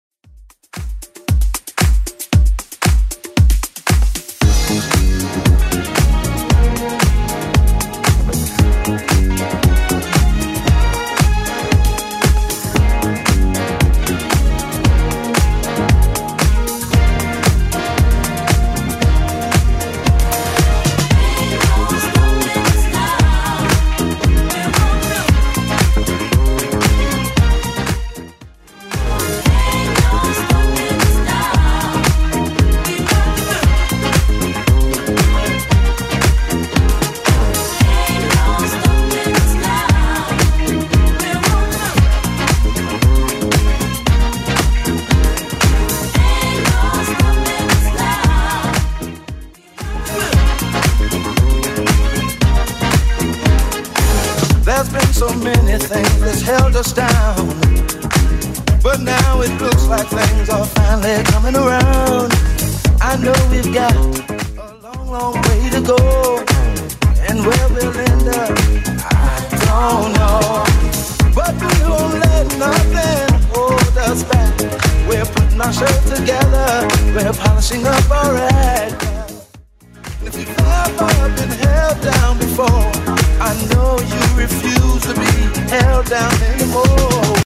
BPM: 115 Time